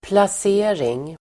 Uttal: [plas'e:ring]